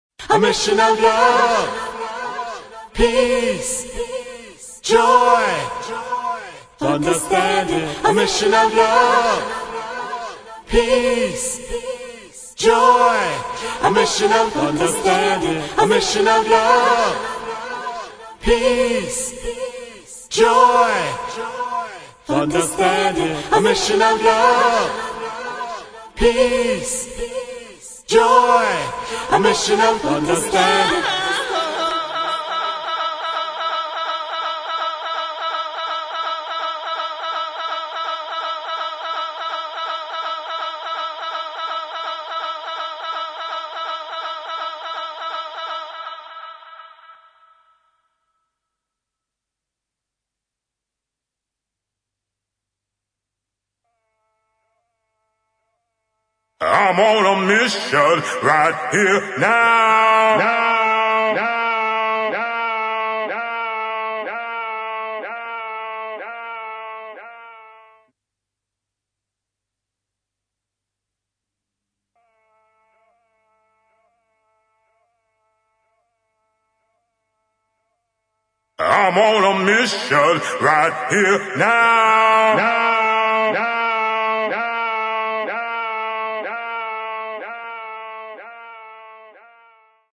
[ HOUSE | DISCO ]